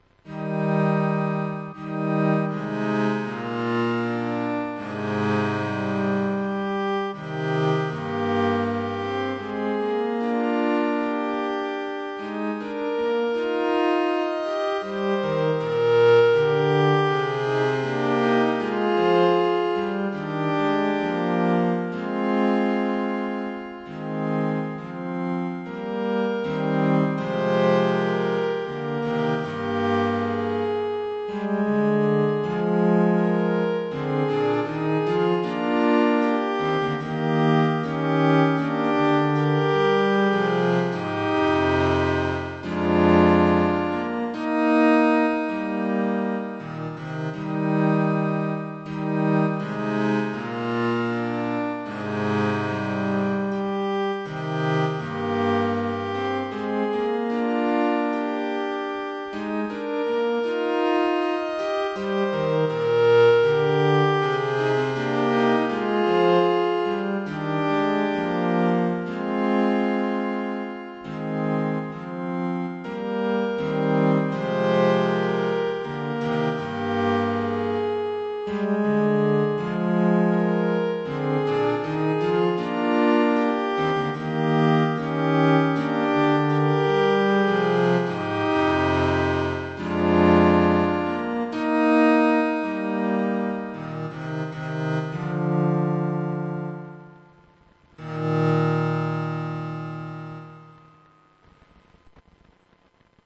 hymn #211 from the Lutheran Book of Hymns
THE CADENZA MIDI DIARY